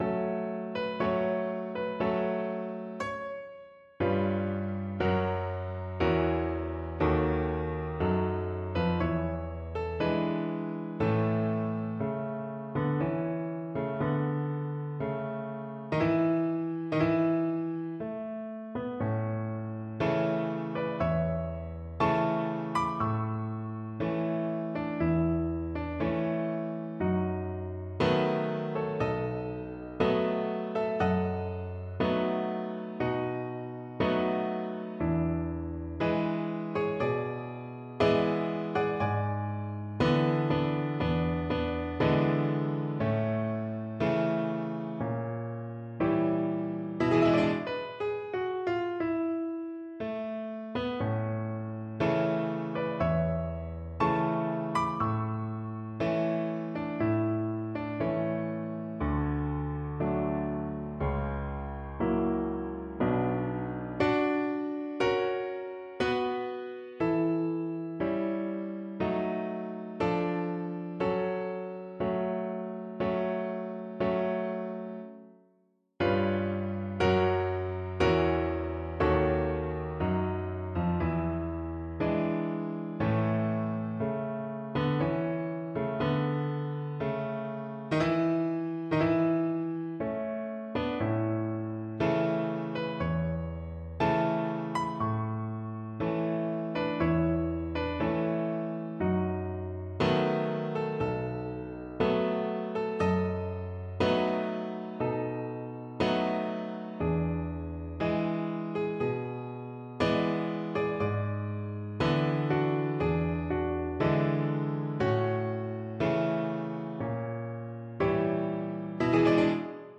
is a popular song
Allegretto
4/4 (View more 4/4 Music)
Arrangement for Violin and Piano
100 B.P.M.